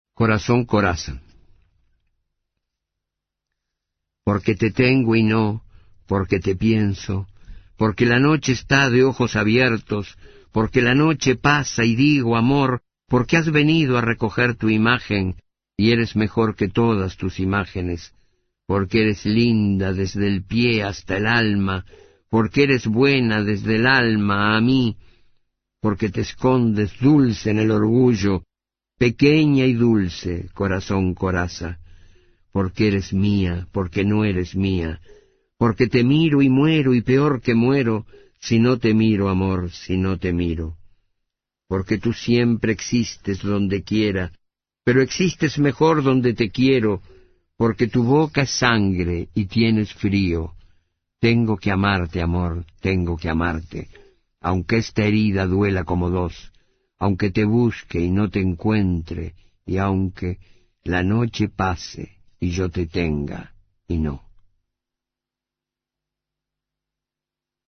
Autor del audio: el propio autor